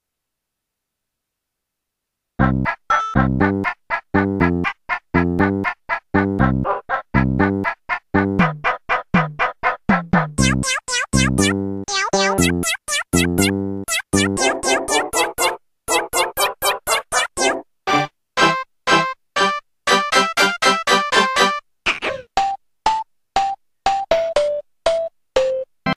2026年04月23日 みょんみょんの曲 日曜第一でクイズの時に流れてるアレ みょんみょんの曲 （0：25） フルバージョンなのでいつものクイズのところは カットしてお使いください PR BGM 2014年12月04日 Responses0 Responses お名前 タイトル メールアドレス URL パスワード